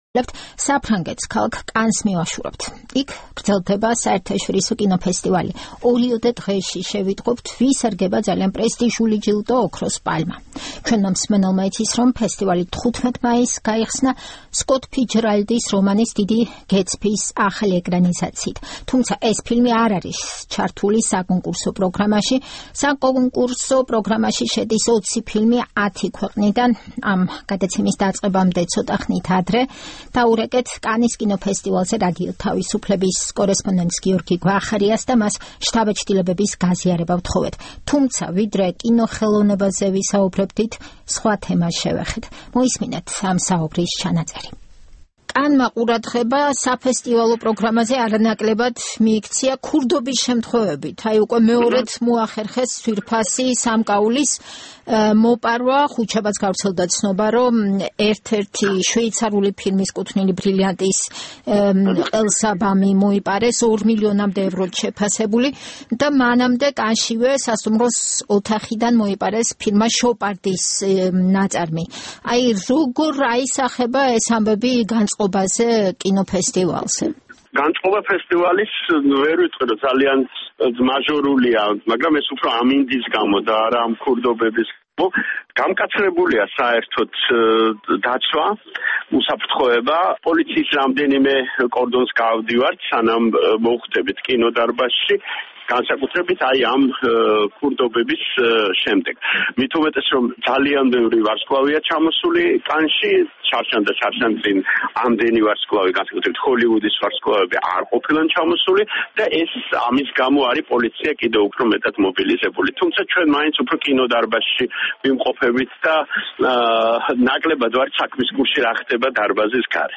რეპორტაჟი კანის საერთაშორისო კინოფესტივალიდან